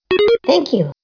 voice_credit_thankyou.wav